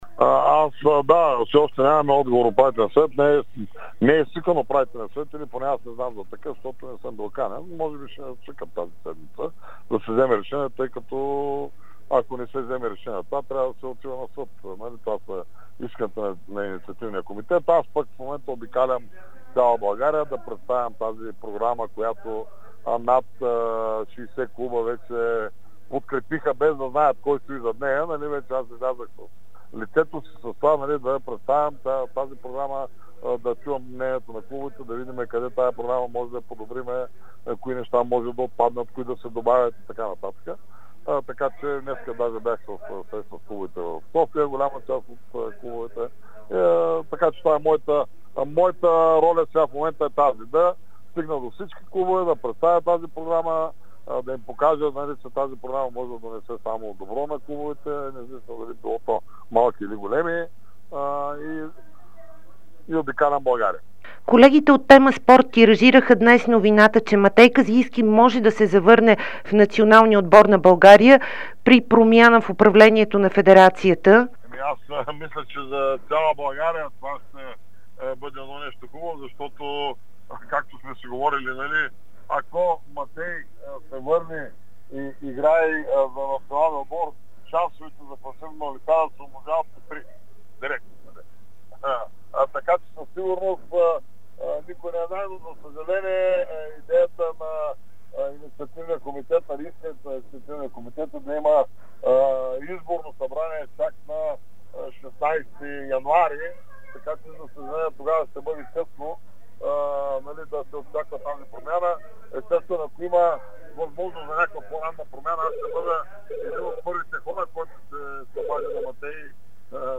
Кандидатът за нов президент на българската федерация по волейбол Любо Ганев говори пред dsport и Дарик радио по темата със завръщането на Матей Казийски в националния отбор по волейбол, както и за това какво ще се случи родния ни волейбол.